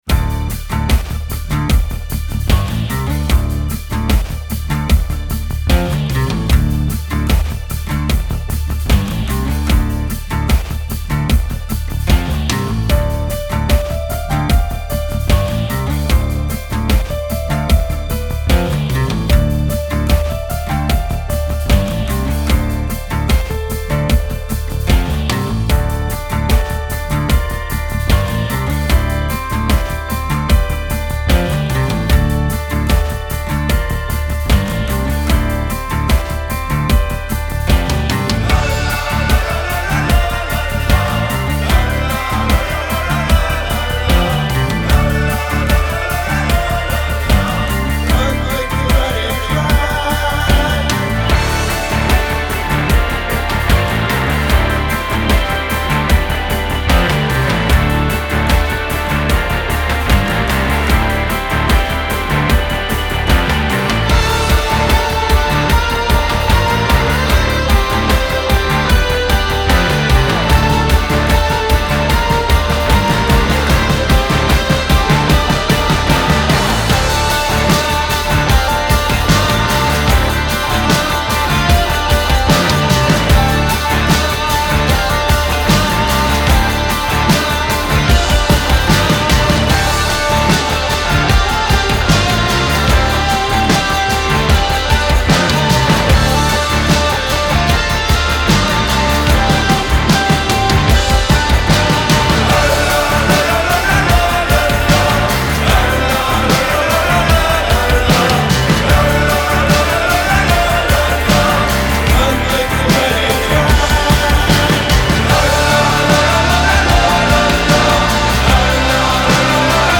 Genre : Alternative, Indie